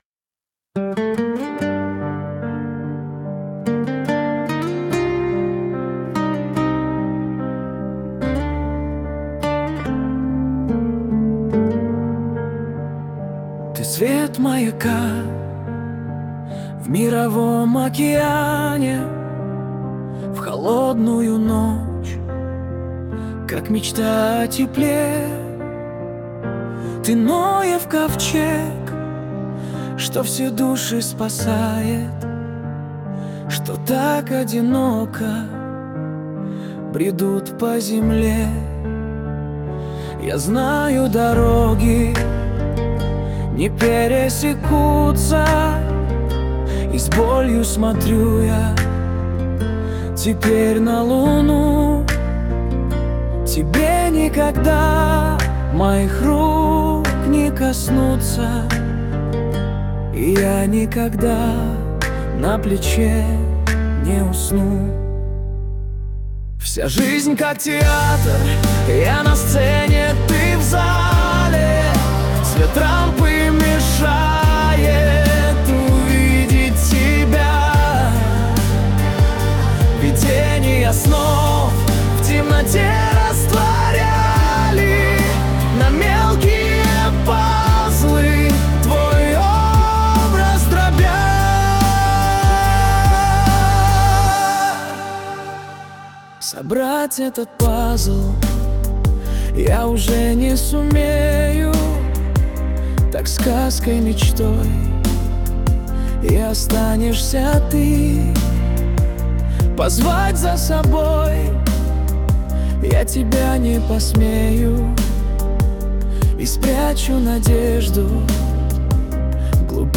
Музыка и исполнение ИИ.
надо же... очень натурально!